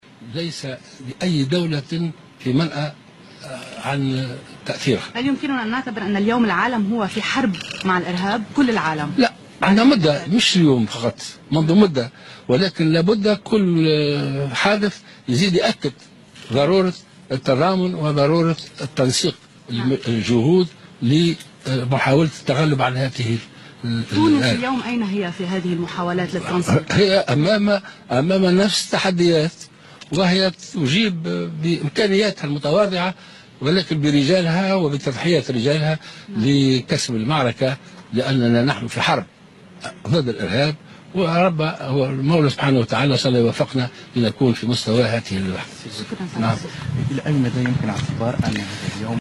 واعتبر قائد السبسي في تصريح إعلامي، أن هجمات باريس تفرض ضرورة مزيد التعاون بين الدول لتعزيز الجهود للتغلب على هذه الظاهرة، مشيرا إلى تونس تواجه نفس التحديات بتضحيات رجالها على الرغم من تواضع إمكانياتها.